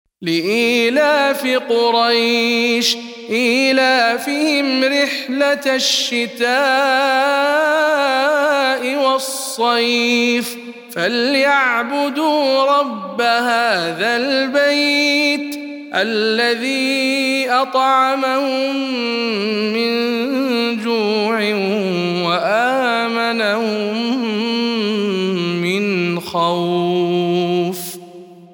سورة قريش - رواية خلاد عن حمزة